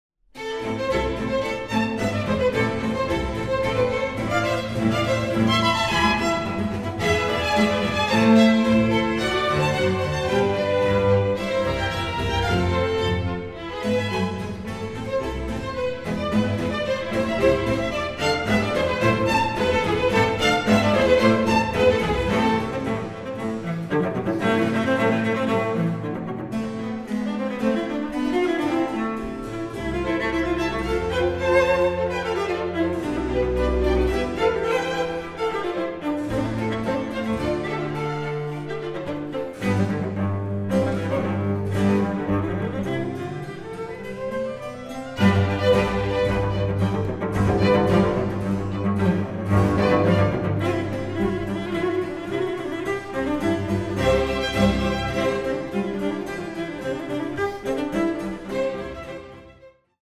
Cello